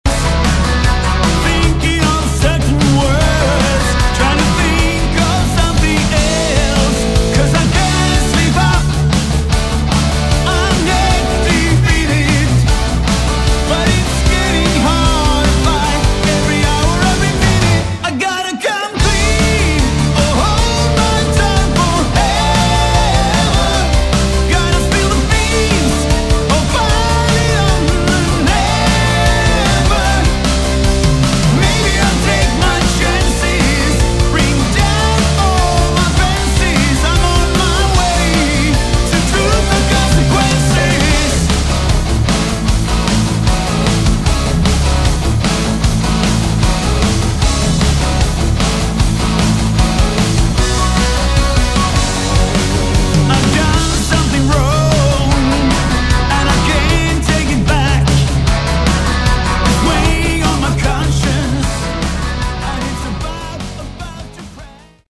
Category: Hard Rock
drums